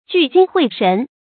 注音：ㄐㄨˋ ㄐㄧㄥ ㄏㄨㄟˋ ㄕㄣˊ
聚精會神的讀法